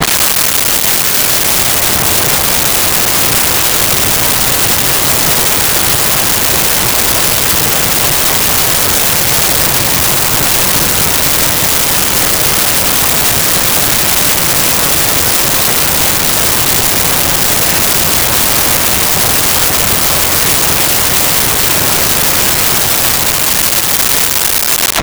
Gravel Pit